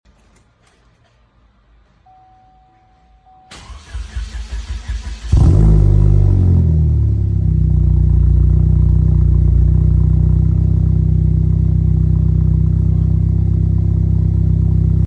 Cobalt SS Big Turbo Cold sound effects free download
Cobalt SS Big Turbo Cold Start Exhaust Sound Pure Sound The Chevrolet Cobalt SS, produced from 2005 to 2010, was a sporty version of the Cobalt, with 2.0L turbo and supercharger engines developing up to 260 horsepower.